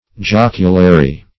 Search Result for " joculary" : The Collaborative International Dictionary of English v.0.48: joculary \joc"u*la*ry\, a. [L. jocularius.